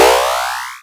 level_up.wav